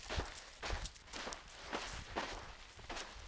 Sand_walk.ogg